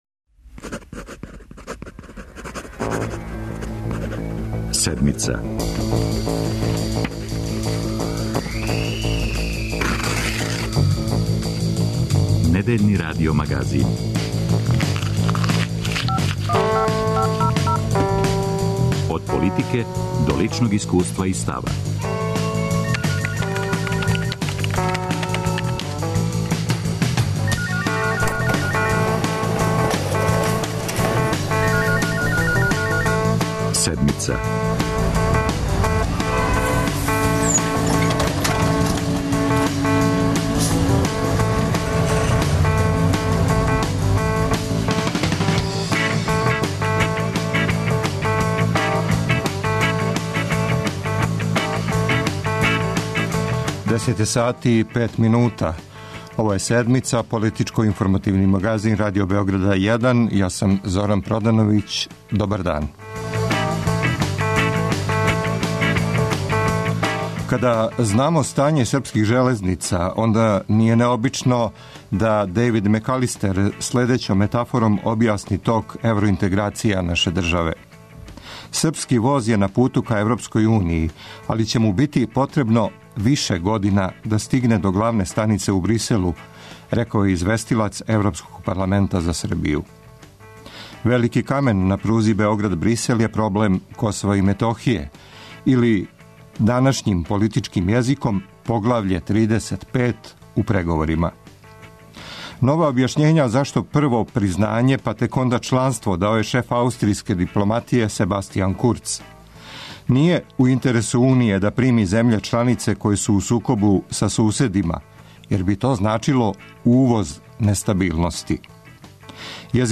Гости: Иво Висковић, Милован Дрецун и Милош Јовановић